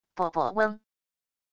啵~~啵~~~~嗡wav音频